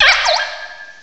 cry_not_shelmet.aif